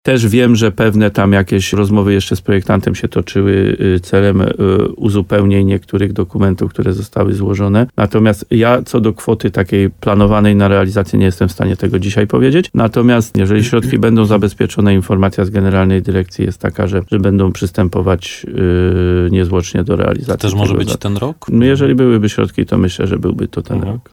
Na razie szacowany koszt inwestycji nie jest znany, bo jej wycena musiała zostać poddana ponownej analizie – dodaje wójt gminy Łososina Dolna.